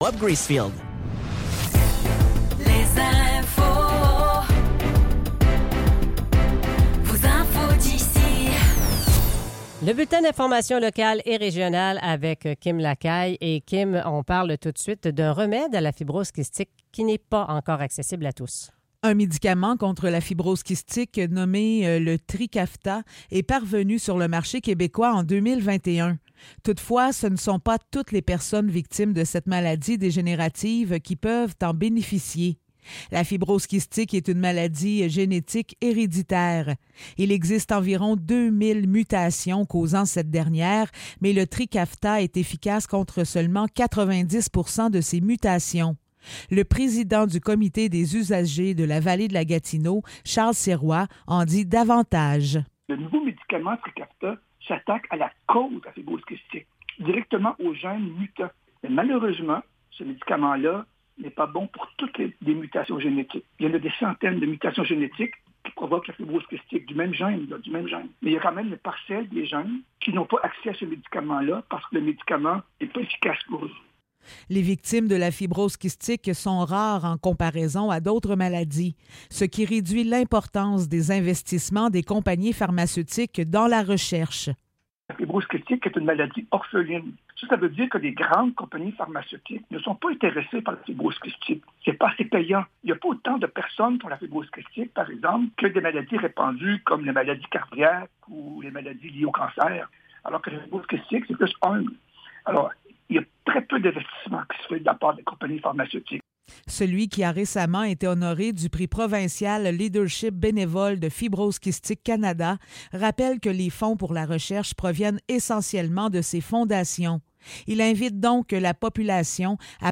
Nouvelles locales - 3 octobre 2024 - 7 h